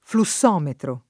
flussometro [ flu SS0 metro ]